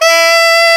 Index of /90_sSampleCDs/Roland L-CD702/VOL-2/SAX_Alto Short/SAX_A.ff 414 Sh
SAX A.FF E0I.wav